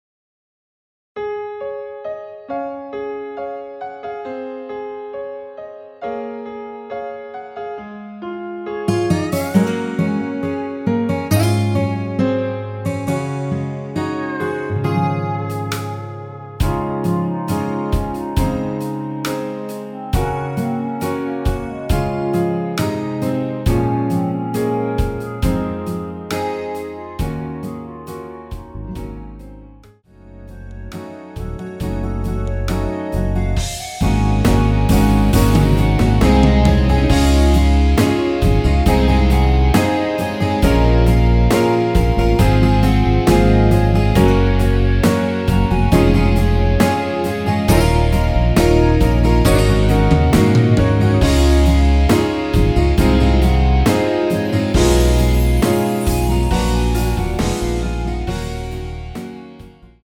원키 멜로디 포함된 MR입니다.(미리듣기 확인)
Db
앞부분30초, 뒷부분30초씩 편집해서 올려 드리고 있습니다.
중간에 음이 끈어지고 다시 나오는 이유는